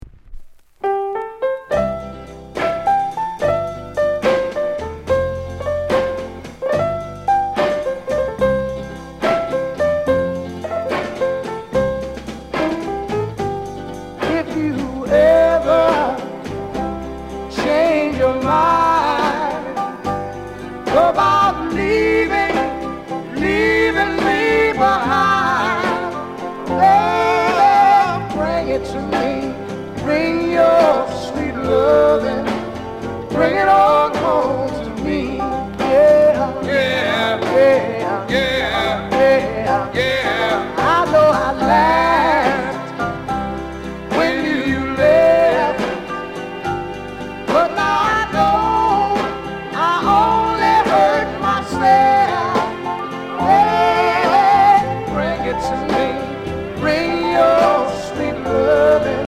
SOUND CONDITION VG
JAMAICAN SOUL